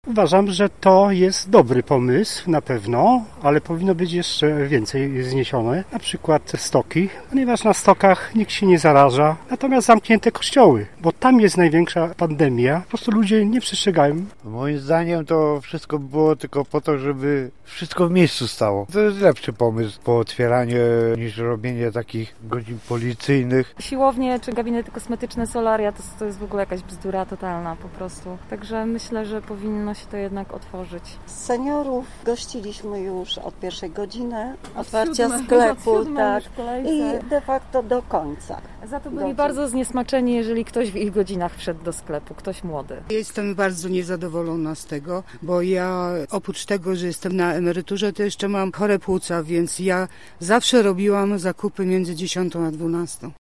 O opinię w tej sprawie poprosiliśmy zielonogórzan, którzy w większości cieszą się z tej decyzji: